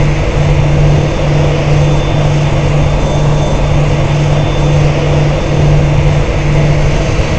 cfm-idleRear.wav